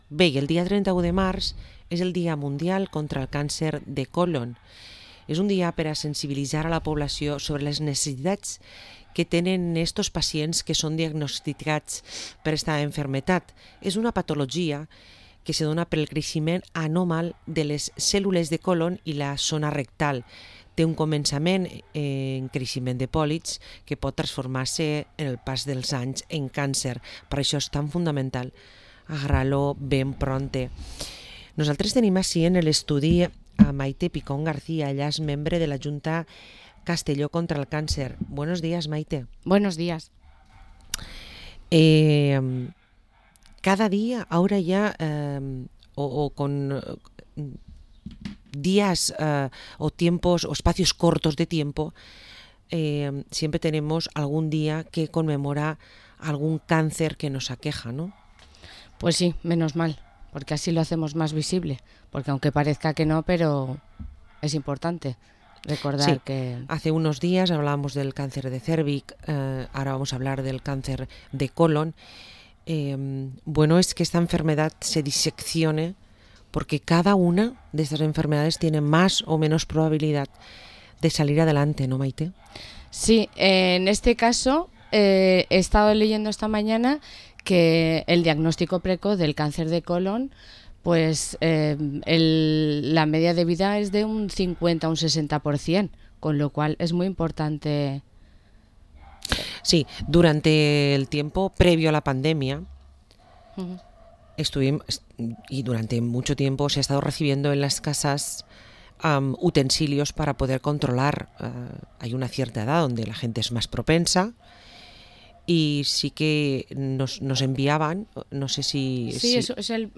Entrevista a la miembro de junta Castellón contra el Cáncer